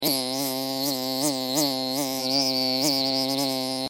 دانلود آهنگ حشره 16 از افکت صوتی انسان و موجودات زنده
دانلود صدای حشره 16 از ساعد نیوز با لینک مستقیم و کیفیت بالا
جلوه های صوتی